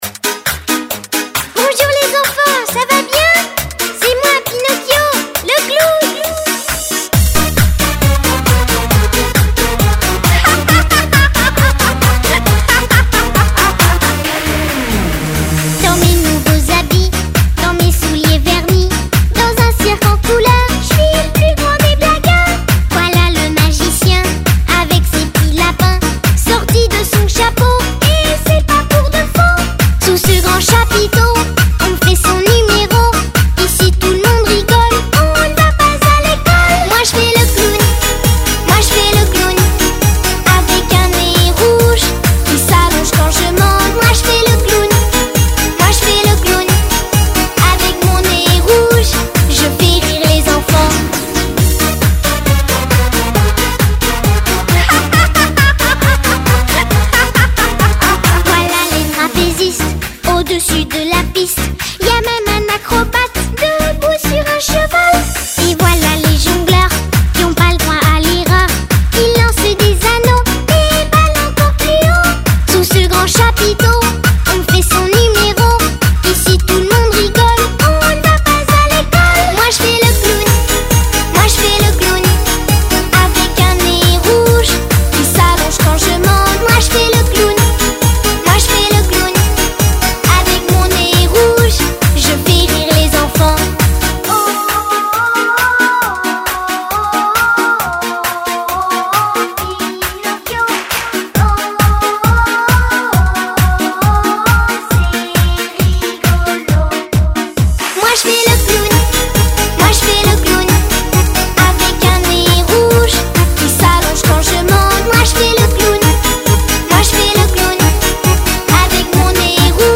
童心未泯的你 进来聆听儿童的流行音乐吧
流行音乐剧、电子舞曲、摇滚与
轻快动人的旋律，
以天真童稚的歌声唱出小朋友心目中对于大人世界的想像，